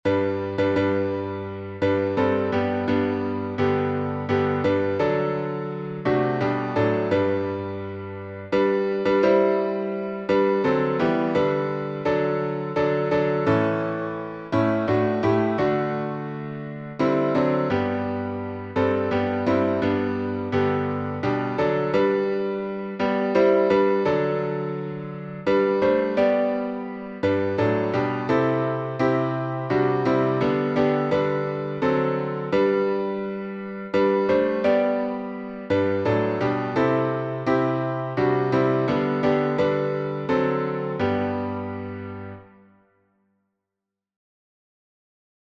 All the Way My Savior Leads Me — G major. Legible, audible sheet music.